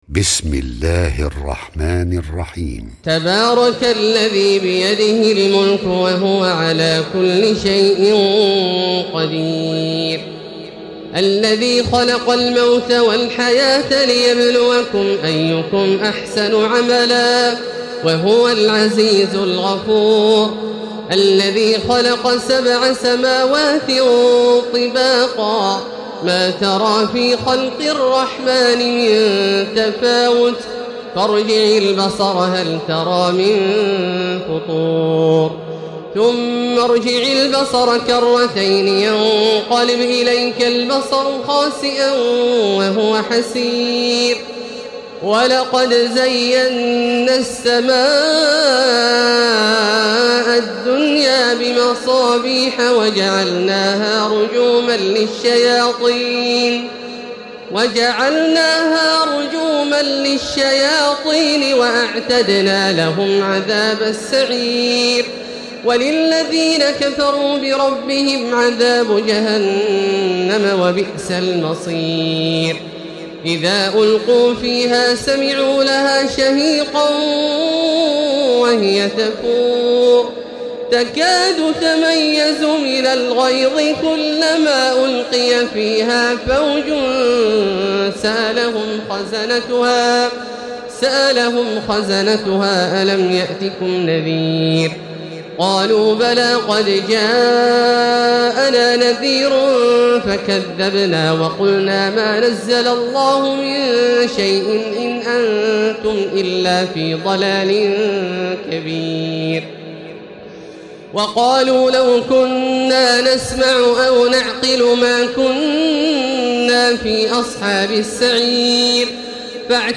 تراويح ليلة 28 رمضان 1436هـ من سورة الملك الى نوح Taraweeh 28 st night Ramadan 1436H from Surah Al-Mulk to Nooh > تراويح الحرم المكي عام 1436 🕋 > التراويح - تلاوات الحرمين